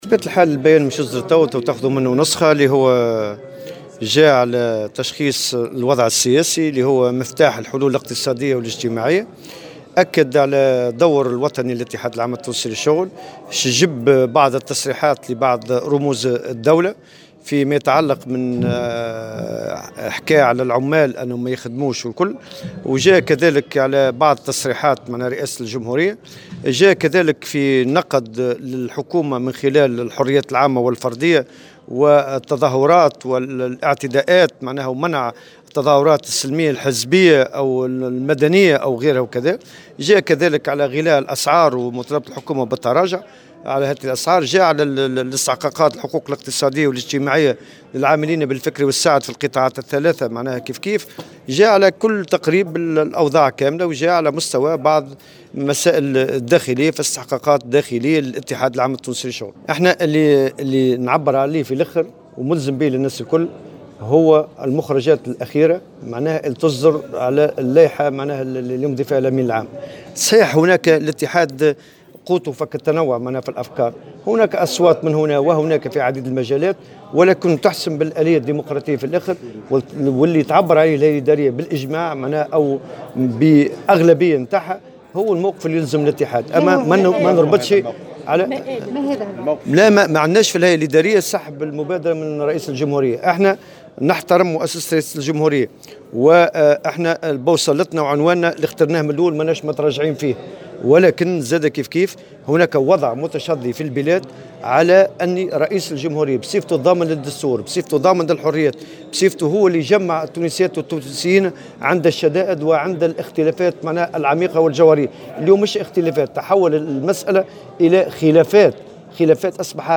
قال الأمين العام للاتحاد العام التونسي للشغل، نور الدين الطبوبي، " لم نسحب مبادرة الحوار الوطني من رئيس الجمهورية وندعوه إلى المضي فيها وإن تواصلت الأزمة يجب إعادة الأمانة للشعب بتنظيم انتخابات مبكرة"، وذلك في تصريح إعلامي أدلى به عشية الخميس عقب انتهاء أشغال الهيئة الإدارية للاتحاد المنعقد بالحمامات.